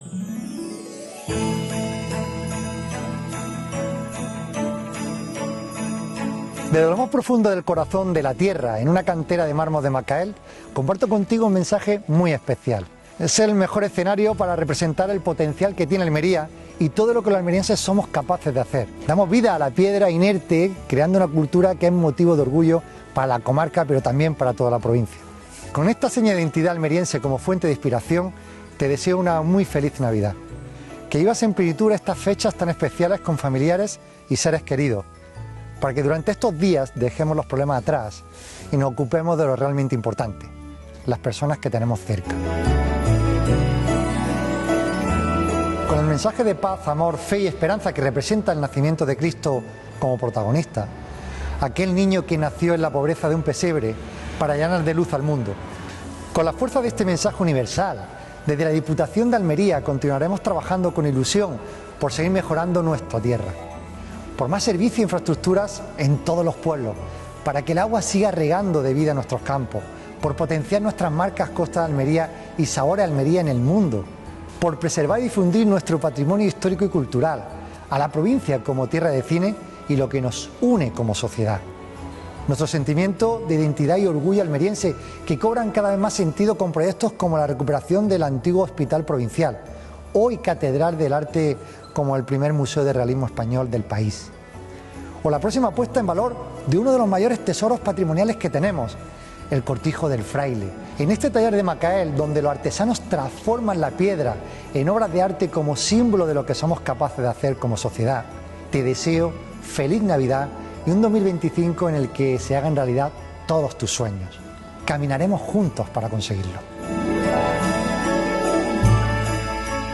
El presidente de Diputación felicita la Navidad a todos los almerienses desde Macael - Blog Diputación de Almería
mensaje_de_navidad_presidente_diputacion.mp3